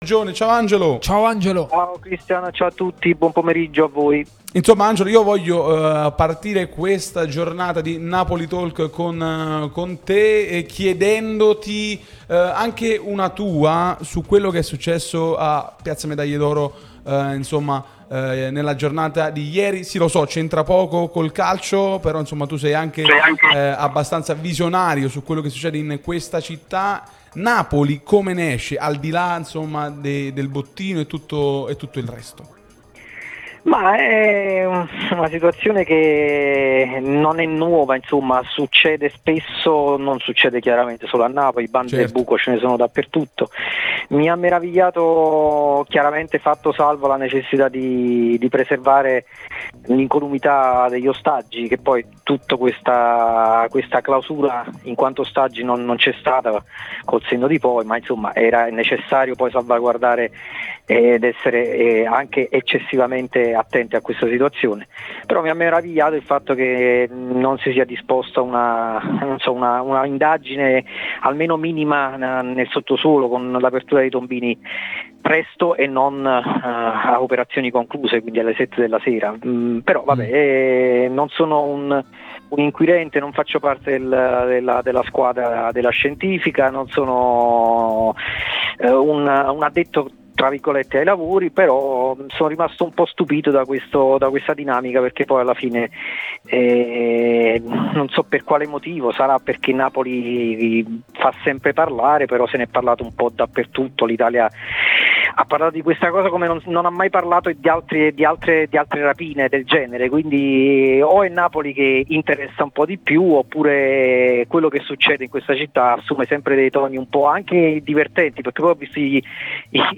è intervenuto sulla nostra Radio Tutto Napoli, prima radio tematica sul Napoli,